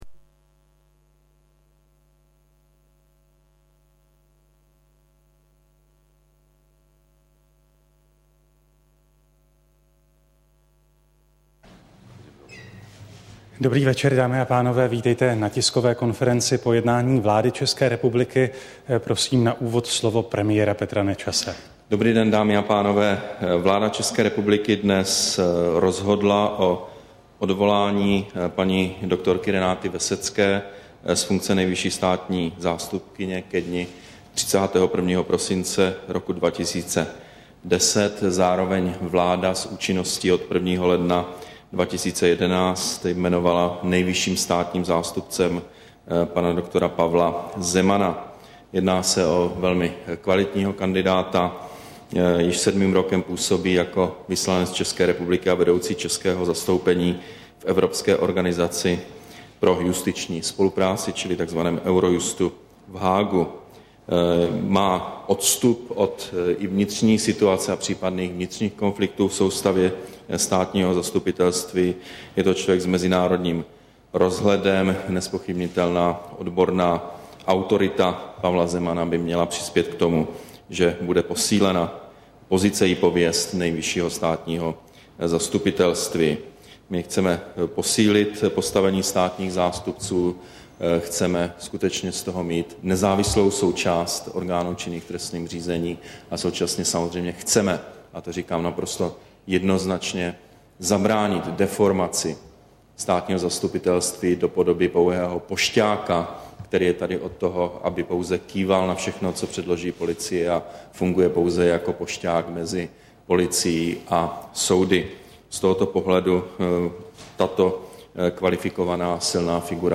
Tisková konference po jednání vlády, 27. října 2010